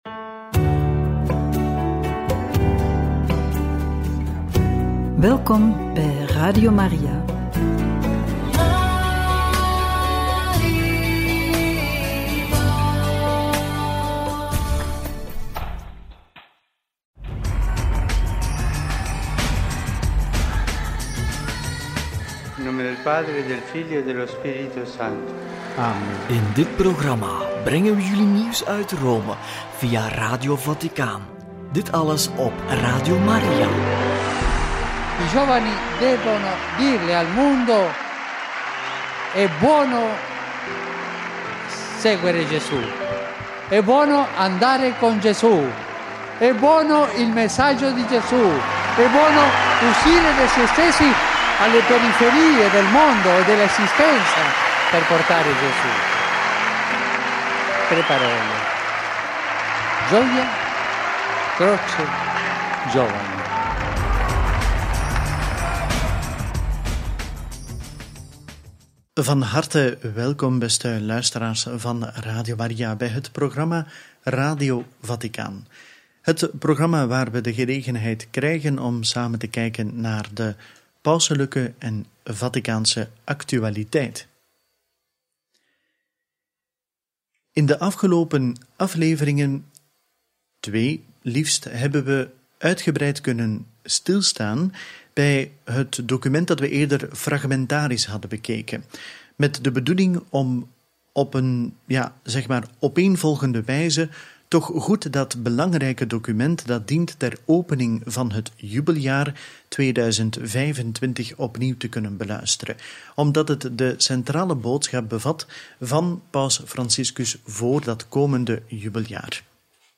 Generale audiëntie – Hoogfeest van Petrus en Paulus, homilie – Radio Maria
generale-audientie-hoogfeest-van-petrus-en-paulus-homilie.mp3